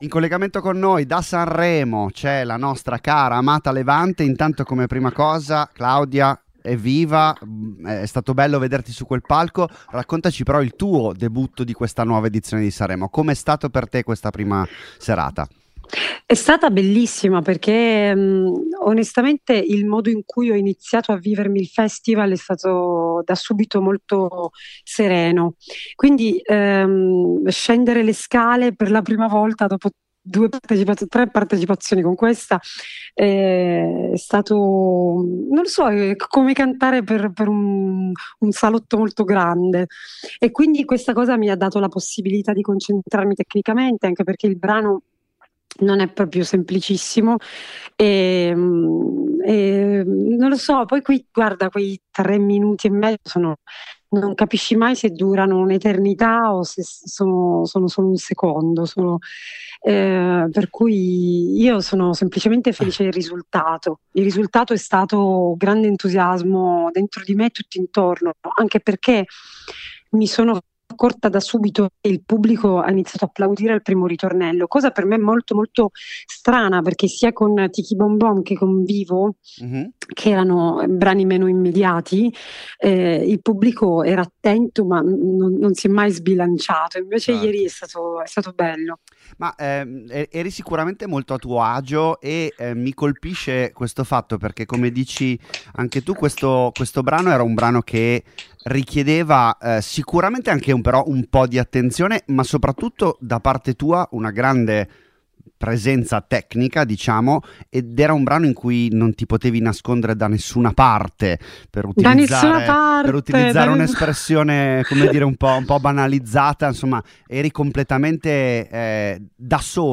All'indomani della partenza della 76esima edizione del Festival di Sanremo, a Volume abbiamo contattato una delle concorrenti in gara ovvero Levante, che in collegamento da Sanremo ci ha raccontato quella che è stata la sua terza volta all’Ariston.